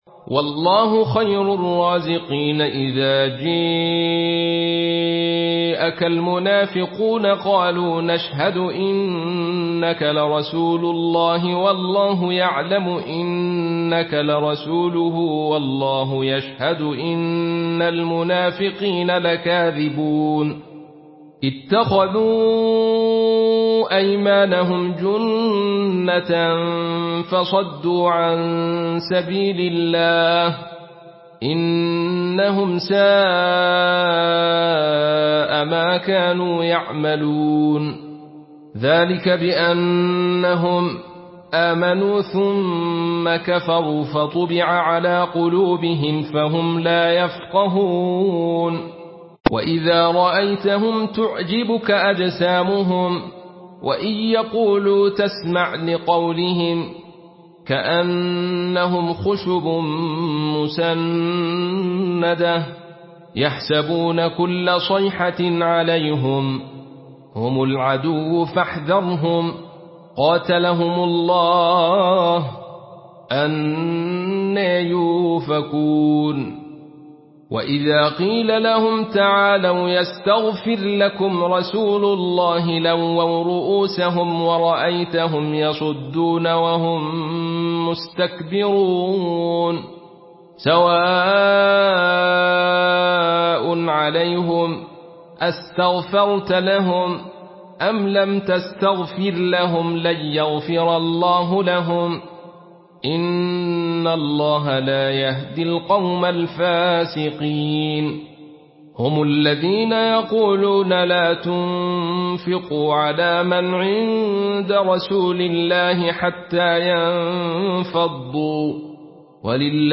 Surah Al-Munafiqun MP3 by Abdul Rashid Sufi in Khalaf An Hamza narration.
Murattal